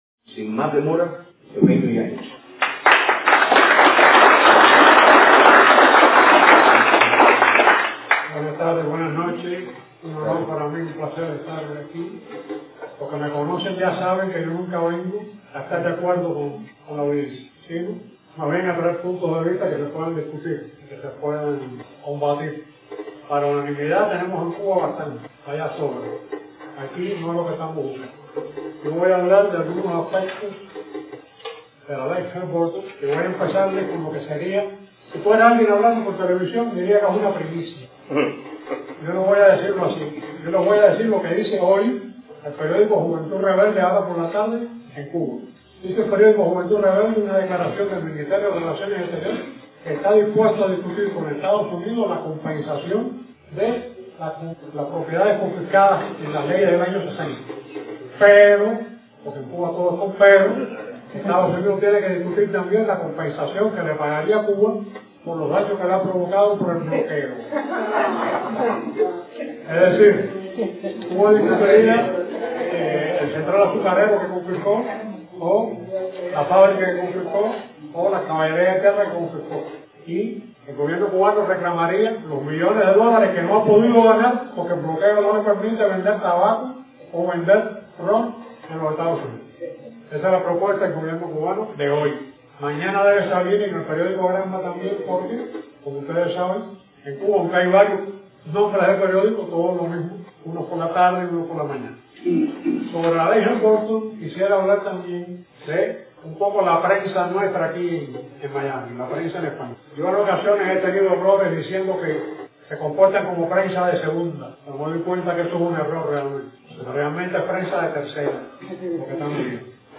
Audios de programas televisivos, radiales y conferencias